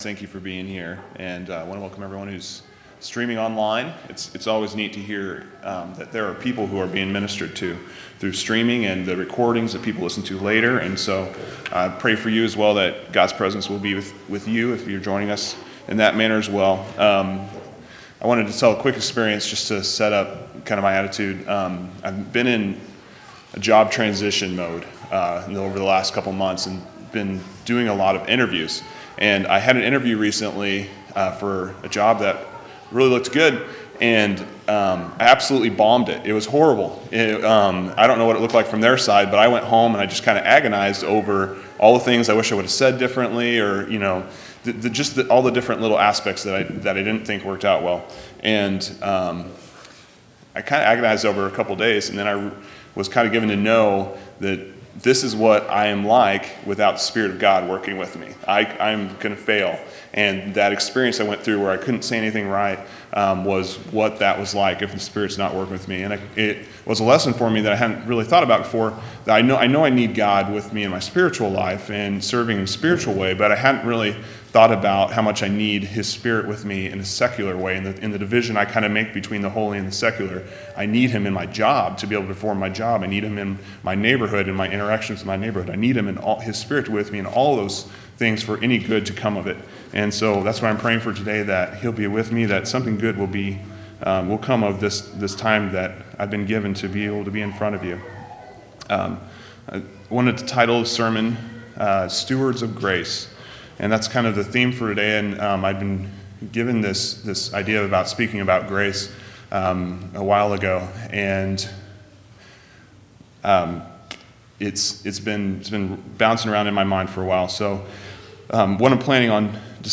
10/29/2017 Location: Temple Lot Local Event